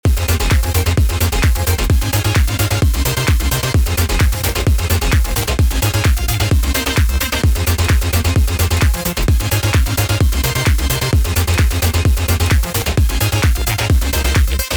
bass house construction kit drops